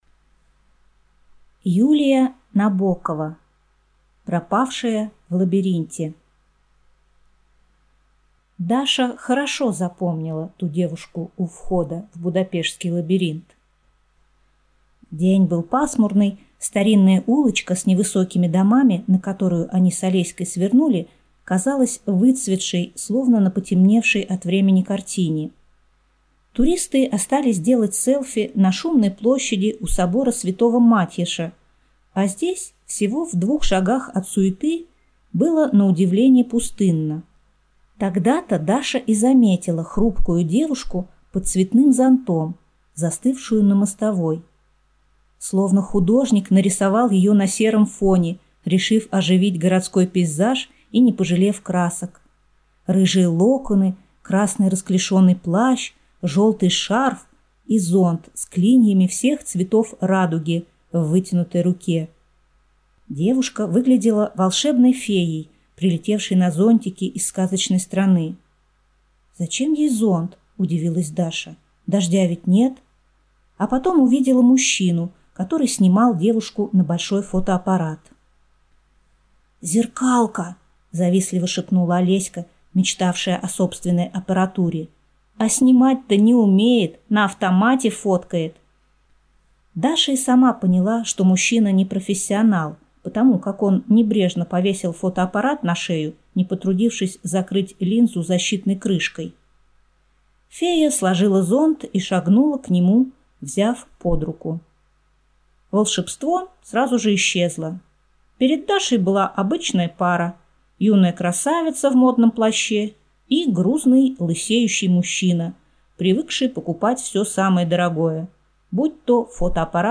Аудиокнига Пропавшая в лабиринте | Библиотека аудиокниг